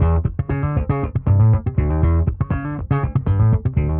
Index of /musicradar/dusty-funk-samples/Bass/120bpm
DF_PegBass_120-E.wav